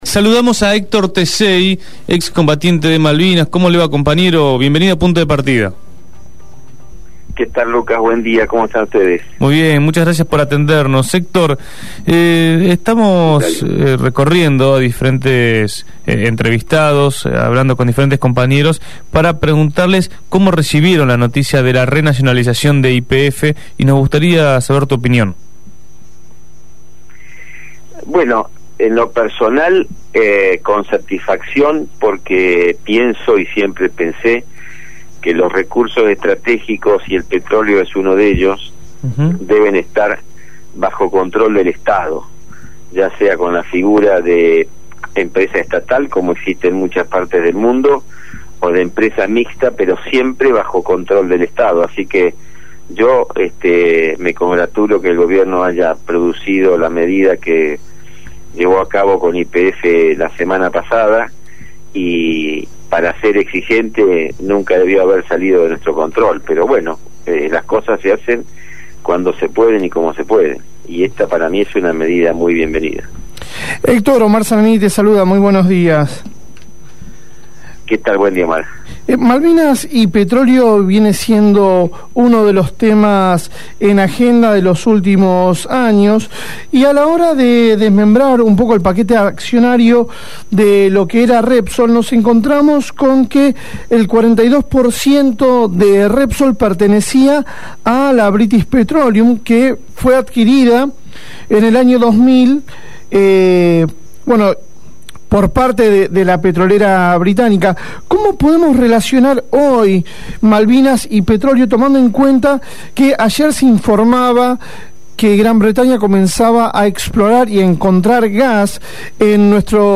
ex combatiente de Malvinas.